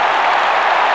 SFX
1 channel
CROWD2.mp3